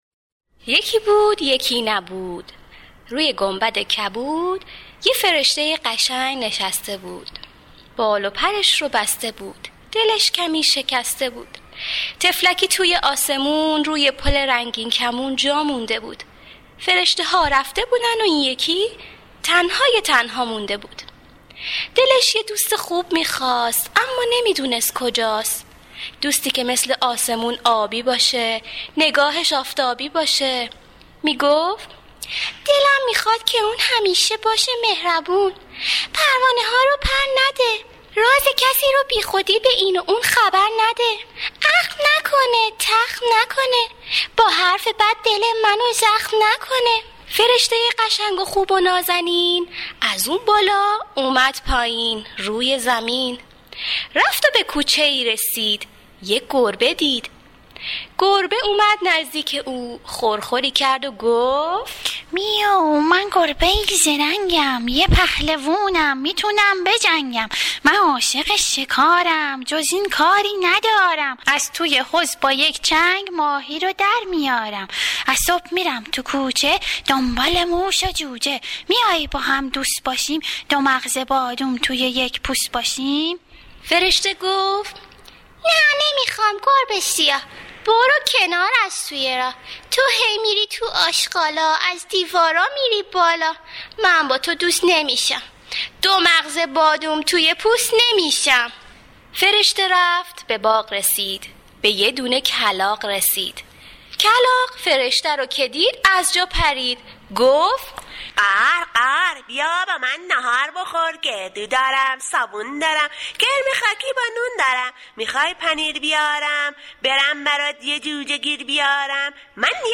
قصه صوتی فرشته ای از آسمون